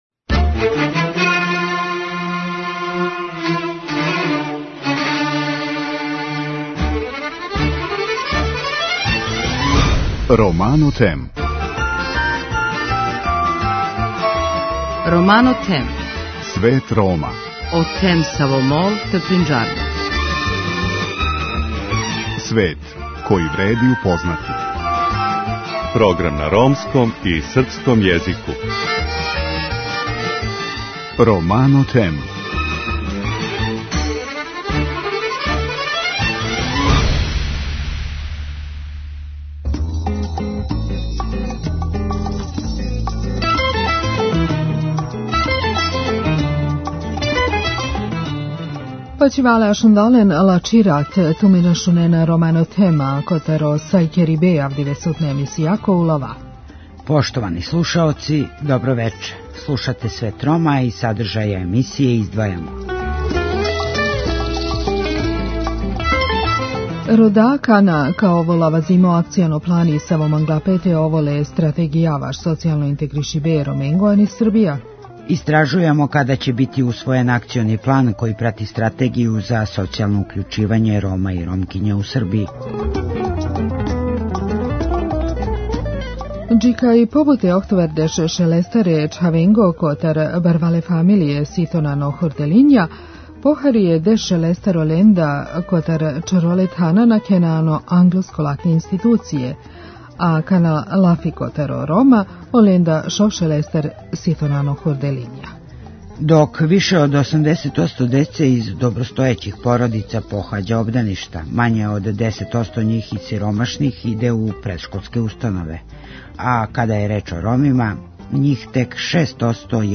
Када ће бити усвојен Акциони план који прати Стратегију за социјално укључивање Рома и Ромкиња у Србији? Разговарамо са Ненадом Иванишевићем, државним секретаром Министарства за рад и социјална питања и председником Савета за унапређење положаја Рома.